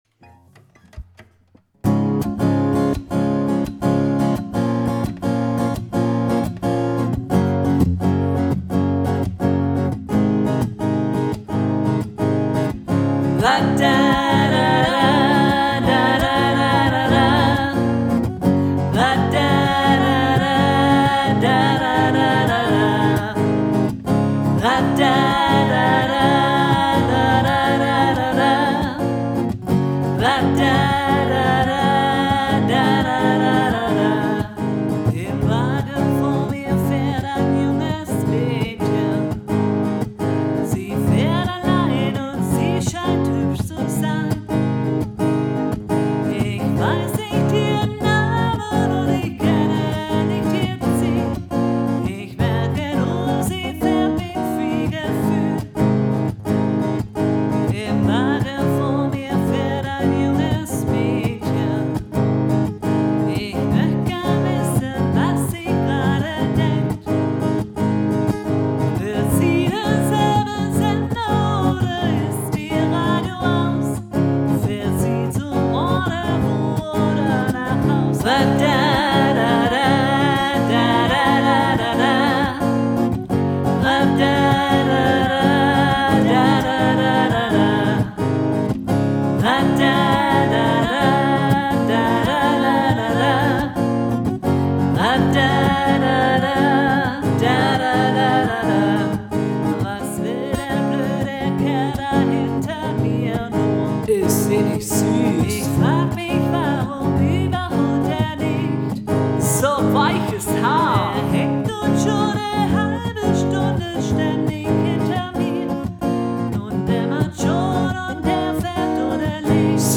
Offenes Singen Im Wagen vor mir 3